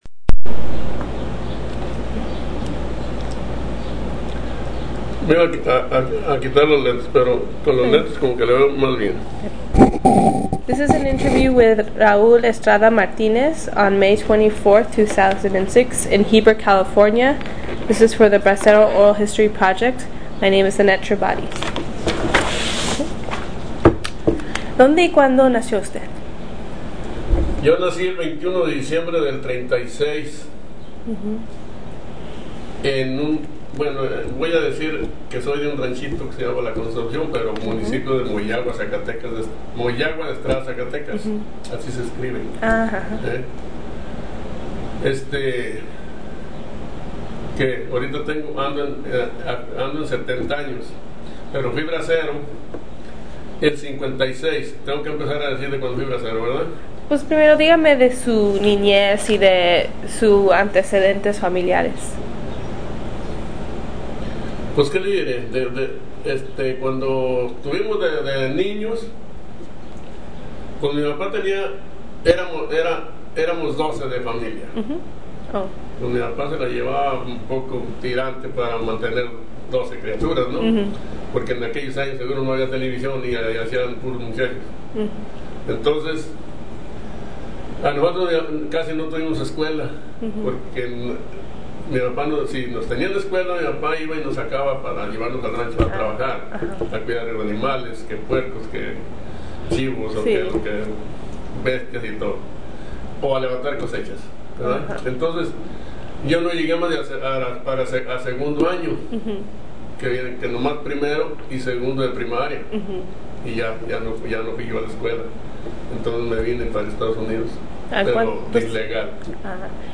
Location Heber, CA Original Format Mini disc